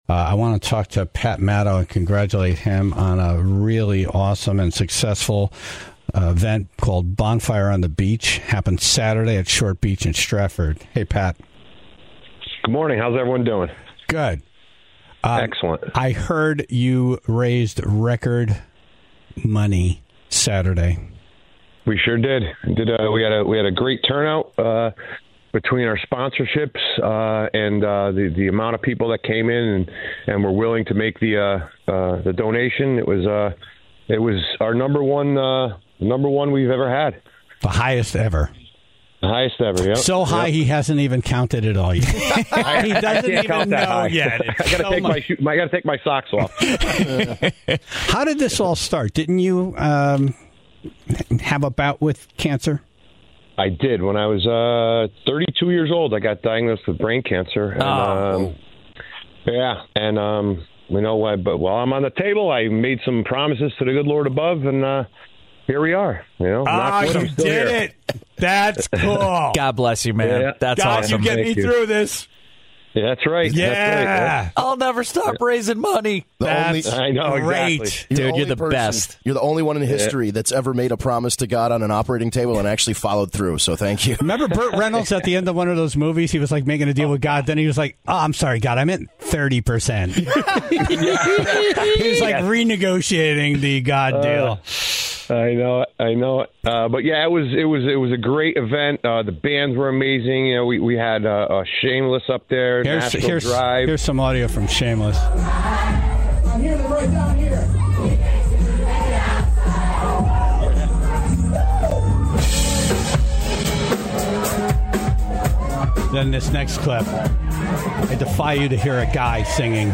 Plus, the Tribe called in their celebrity encounters, including a big hug from Tony Bennett.